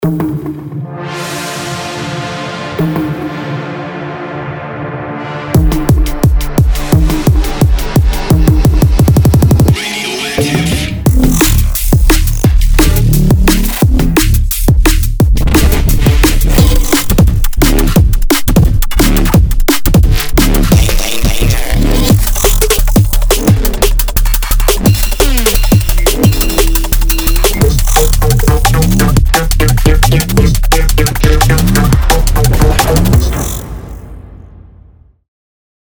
Dubstep
锋利的电子声音是Neurofunk音轨成功的关键。